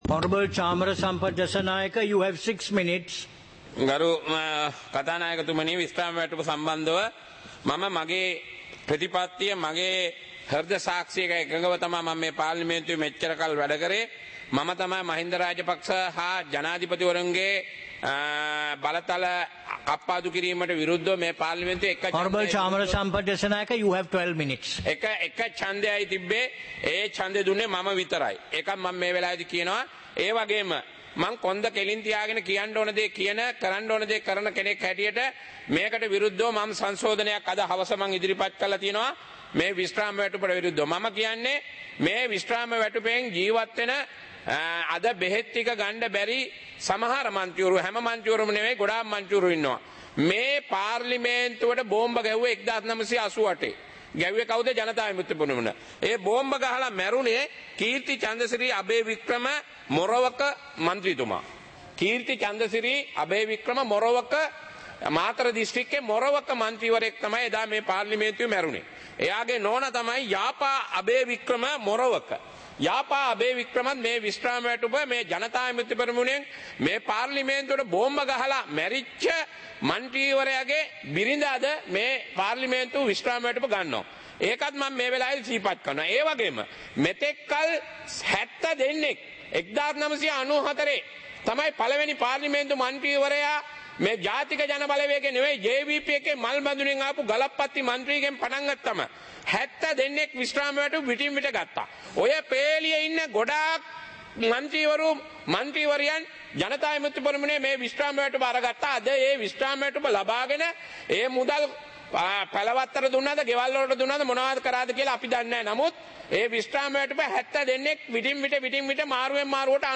Proceedings of the House (2026-02-17)
Parliament Live - Recorded